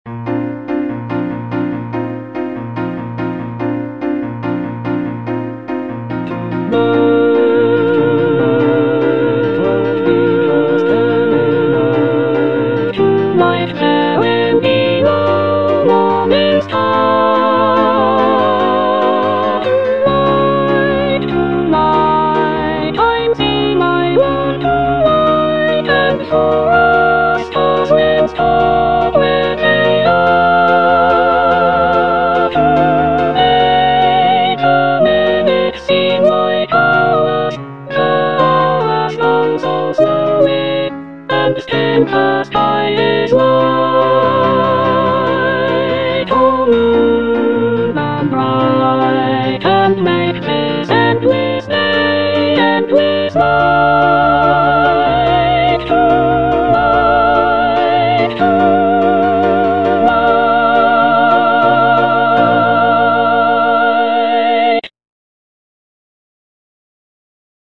Soprano (Emphasised voice and other voices) Ads stop